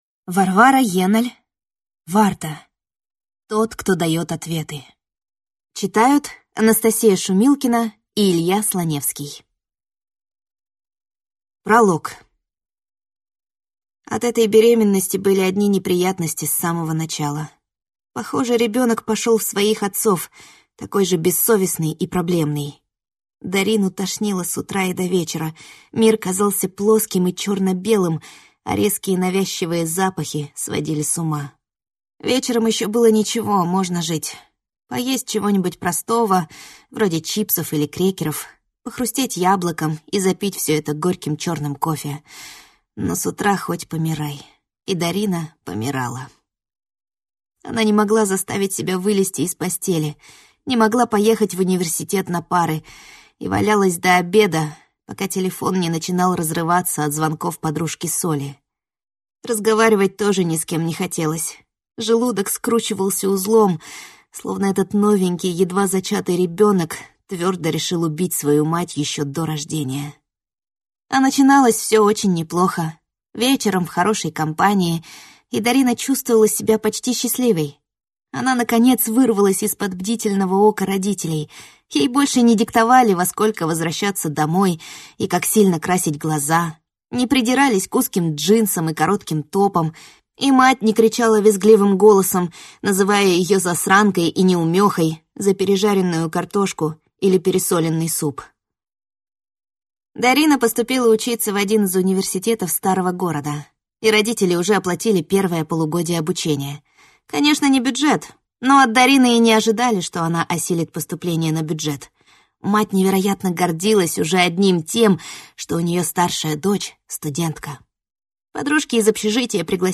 Аудиокнига Тот, кто дает ответы | Библиотека аудиокниг